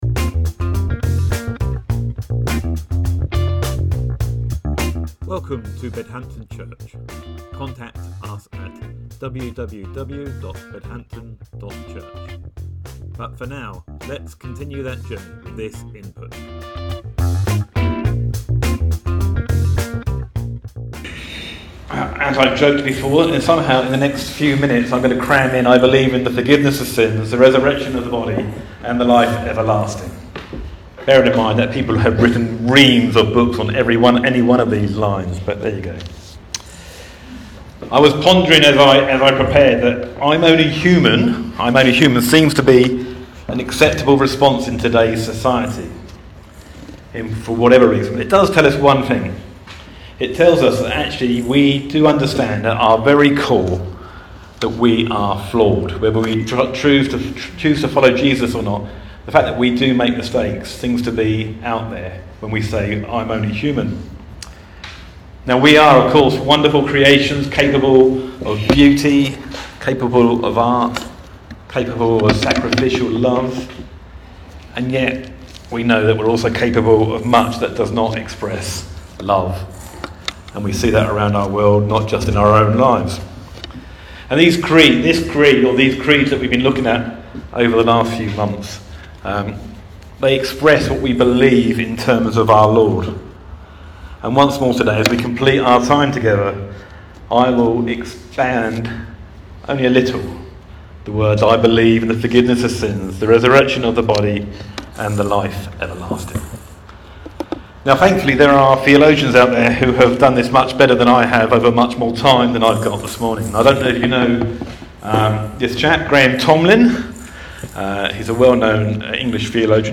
Sermon November 24th, 2024 - Creeds: The forgiveness of sins, the resurrection of the body, and the life everlasting. - Bedhampton Church